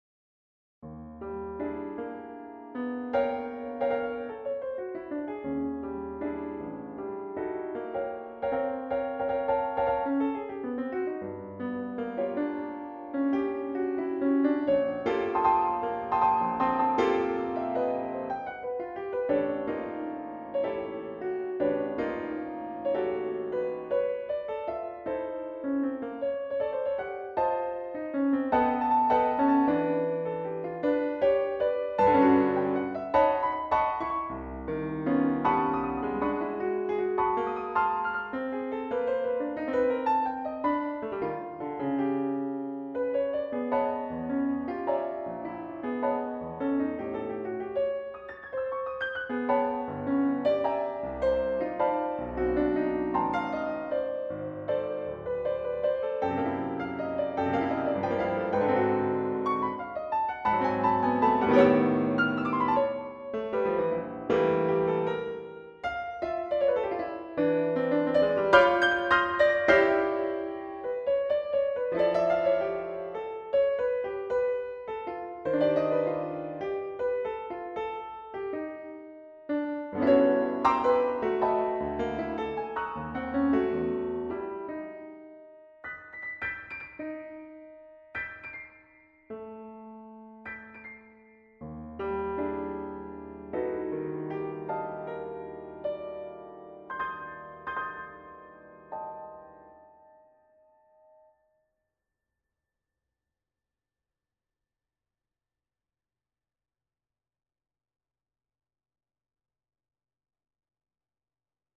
Unperformed work, so just computer realisations (my apologies).